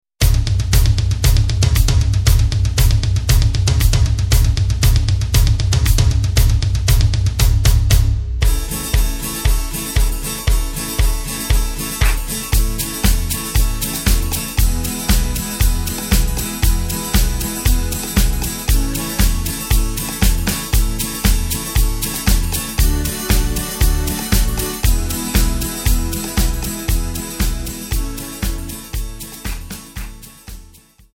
Takt: 2/4 Tempo: 117.00 Tonart: A
Schlager